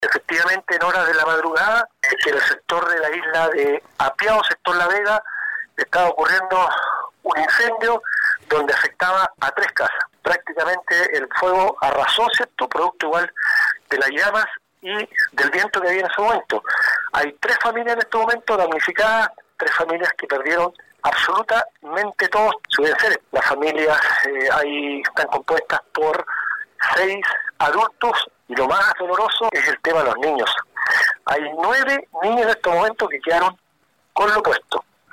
El edil se trasladó a primera hora del domingo junto a un equipo municipal hacia isla Apiao, para poder atender las necesidades de las tres familias, que perdieron todos sus enseres, como lo expresó la autoridad.